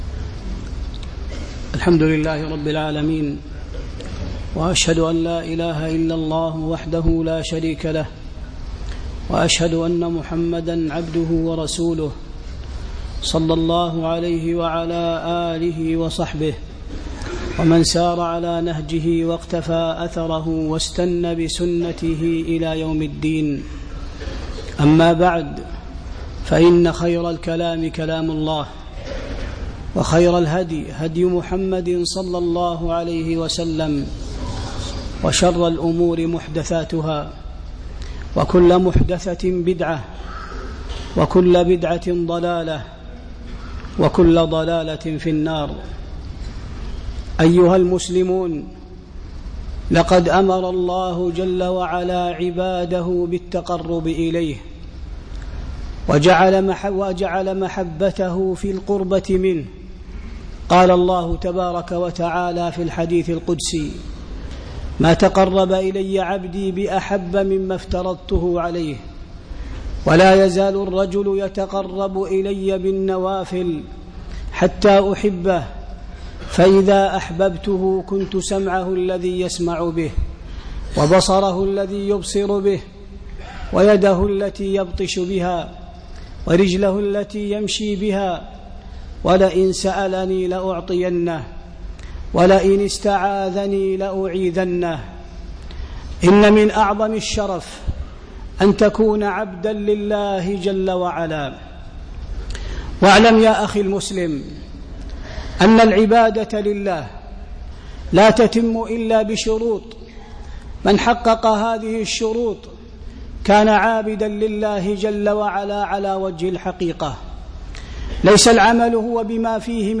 خطبة أهمية الاخلاص والاتباع